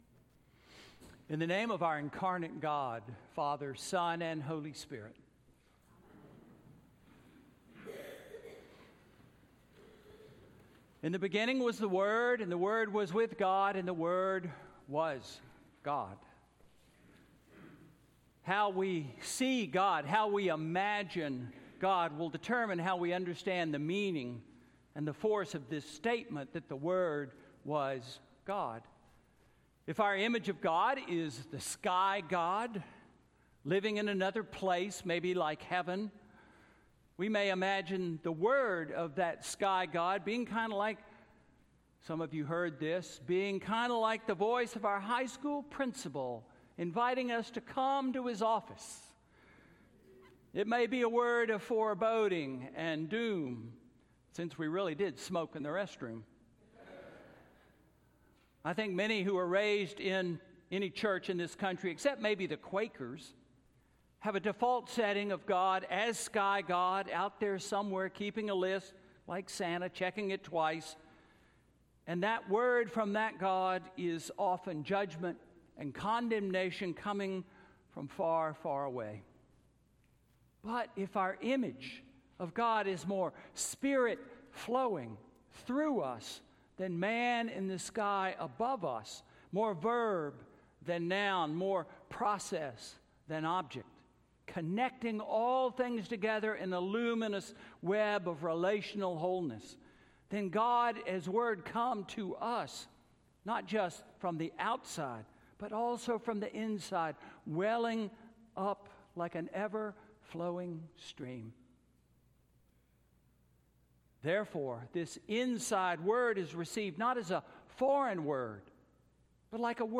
Sermon–God’s Word with Us–December 30, 2918